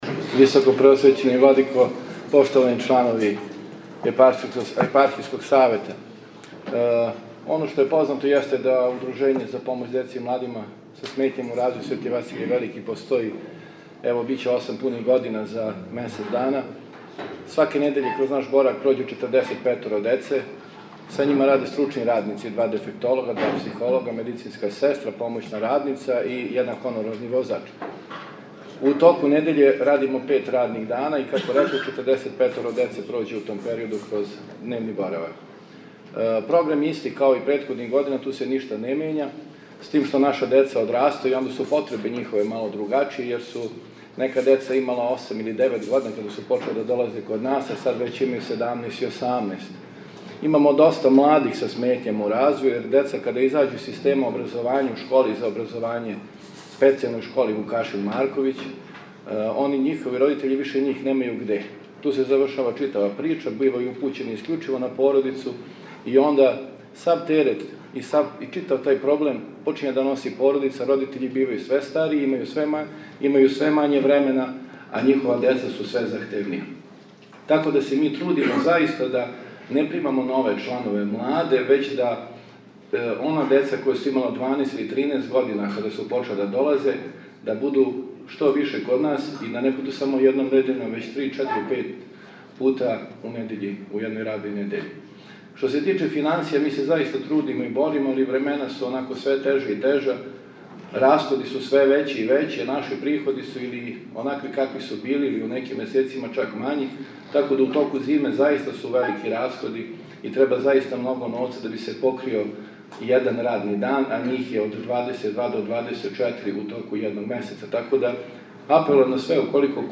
Извештај са седнице Епархијског савета.